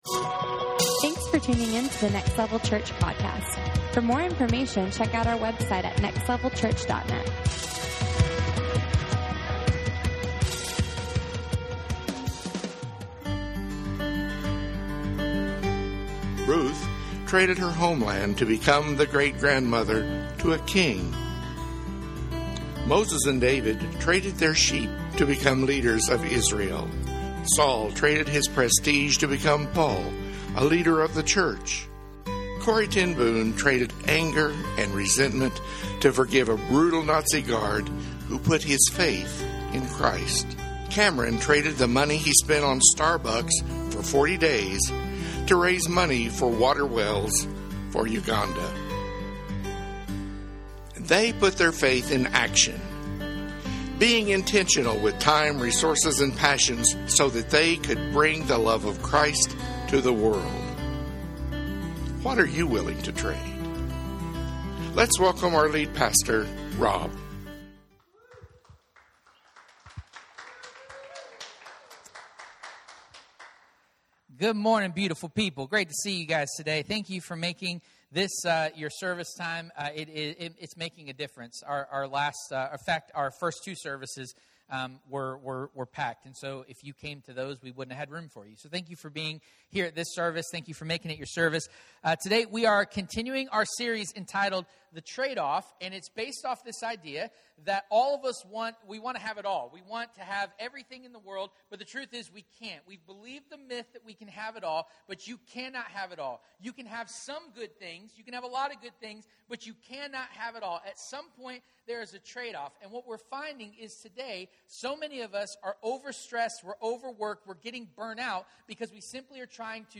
The Tradeoff Service Type: Sunday Morning Watch In every decision